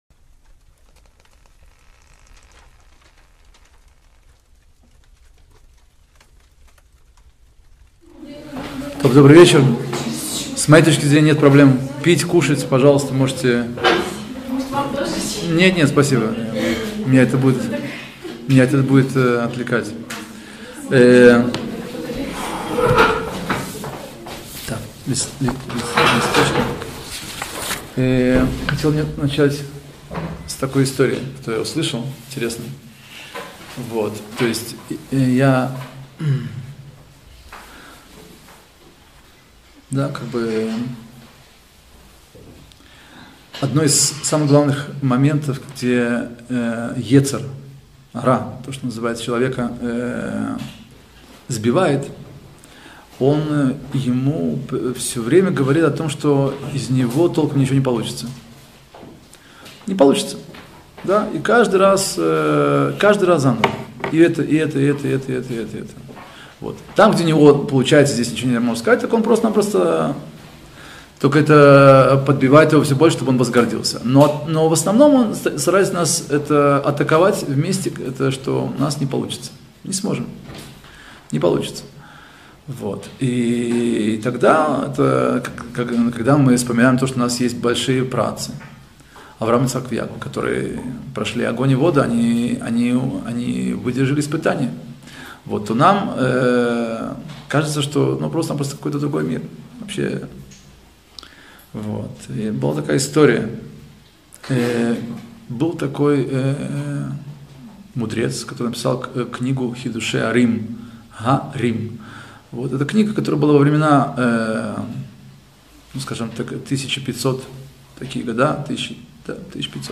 Законы Субботы - Урок 84 – Мукце - различные вопросы - Сайт о Торе, иудаизме и евреях